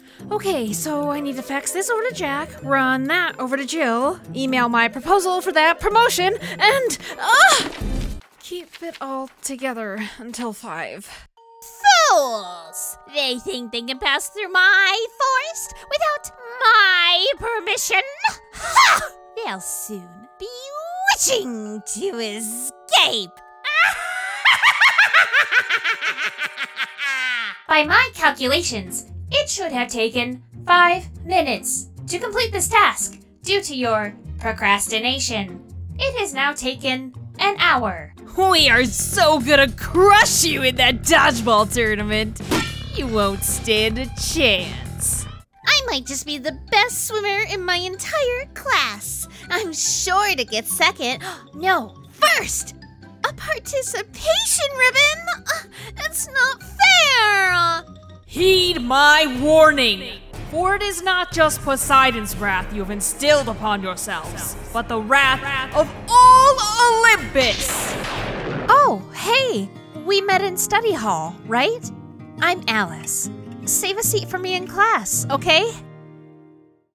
Character Demo
American Standard Midwest & Southern
Child
Teen